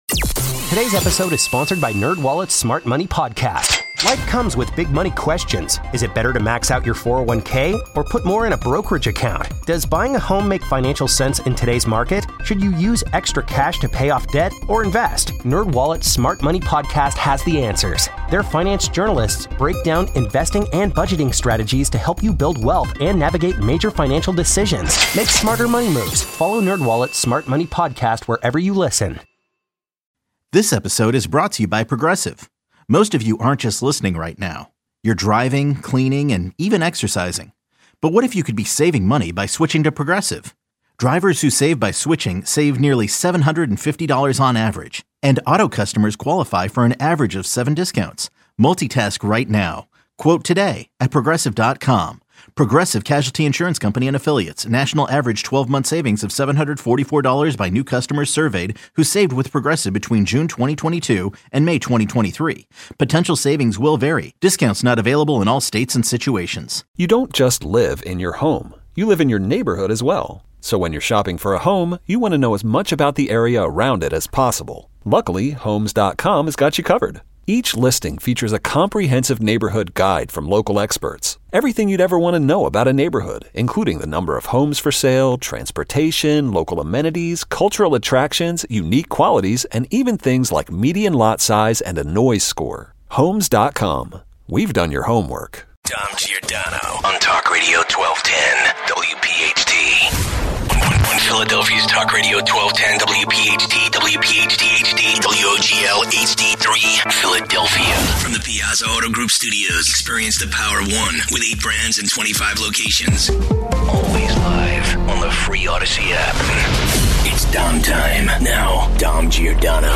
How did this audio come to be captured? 2 - Does Philadelphia have a tag problem? Your calls. 215